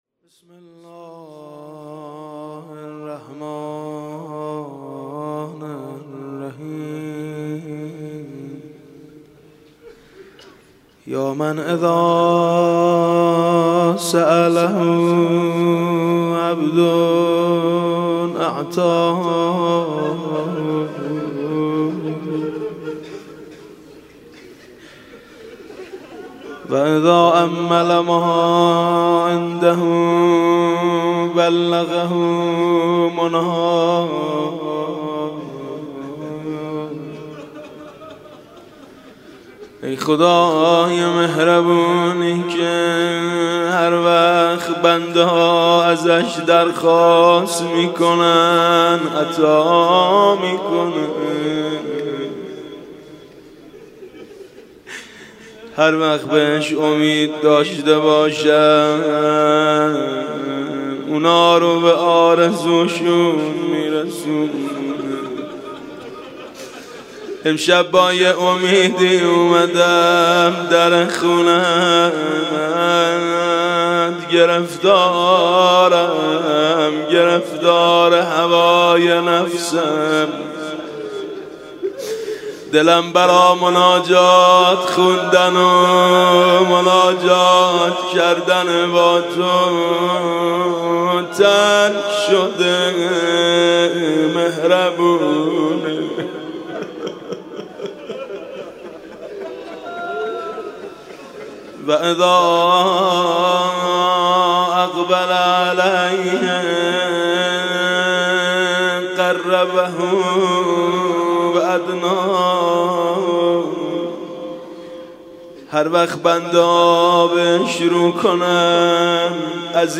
مناجات الراجین (مناجات امیدواران) با صدای حاج میثم مطیعی+ متن و ترجمه